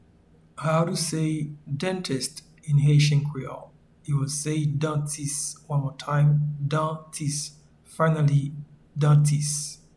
Pronunciation and Transcript:
Dentist-in-Haitian-Creole-Dantis.mp3